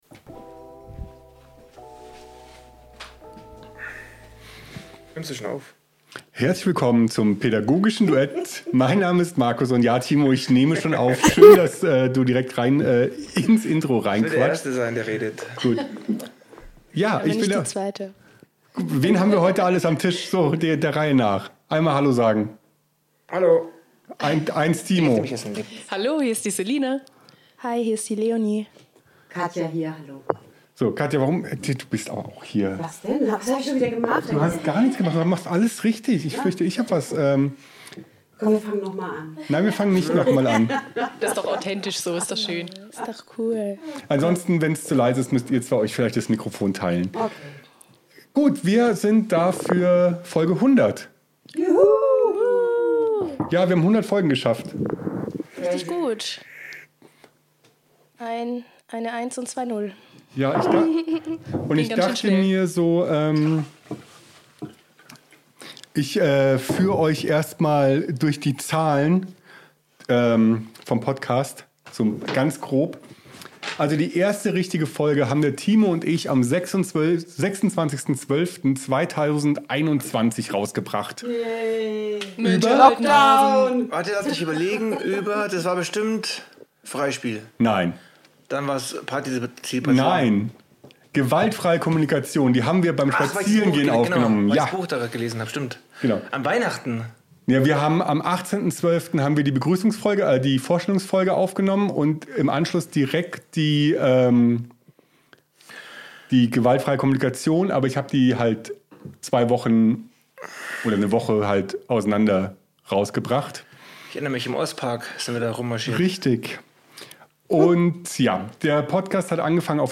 Es ist soweit wir siten zu fünft am Tisch und sprechen über 100 vergangen Folgen. Wie hat sich der Podcast entwickelt, was haben wir vor, unsere Lieblingsfolgen und sehr viel abschweifen. Ach und eine Flasche Sekt.